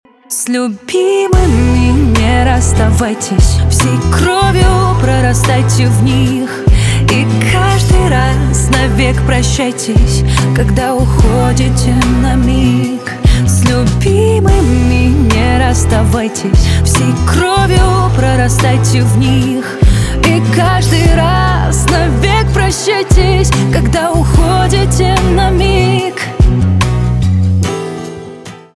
поп
битовые , гитара , чувственные